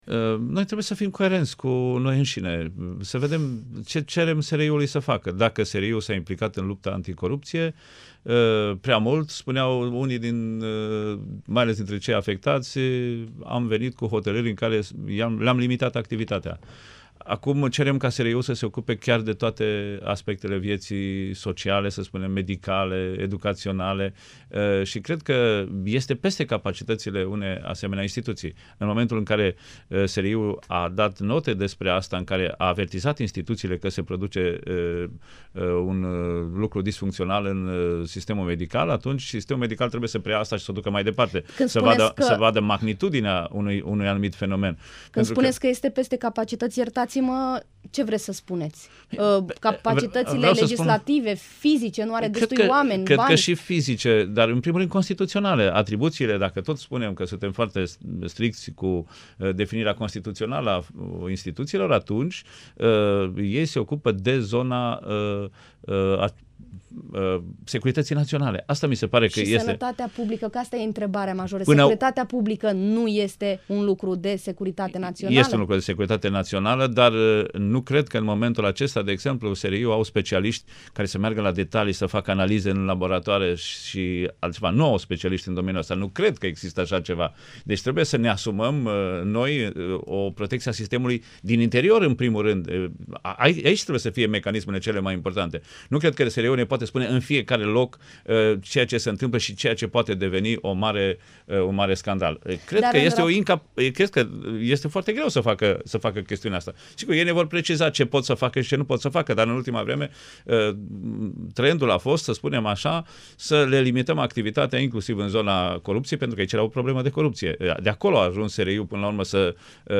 Invitat astăzi  la Interviurile Europa FM, viceprim-ministrul a explicat că, din punctul său de vedere, decizia Curții Constituționale de a interzice SRI să participe la urmărirea penală în alte dosare decât cele de siguranță națională a fost prea bruscă.